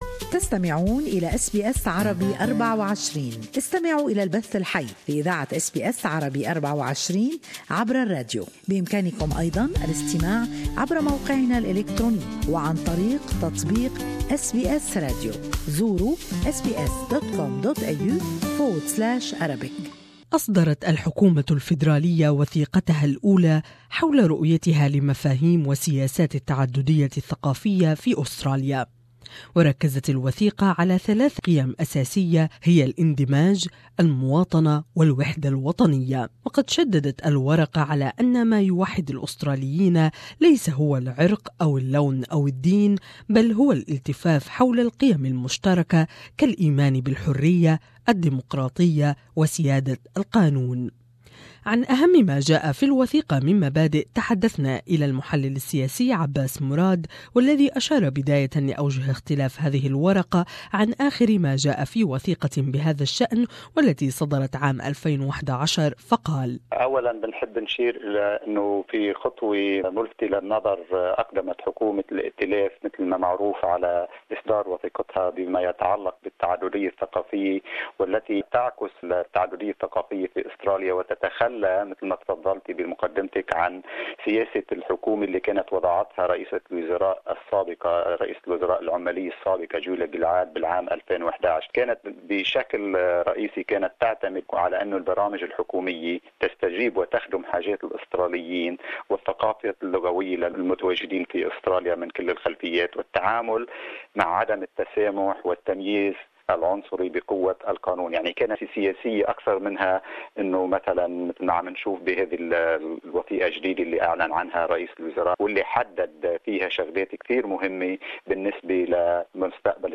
More in this interview with political commentator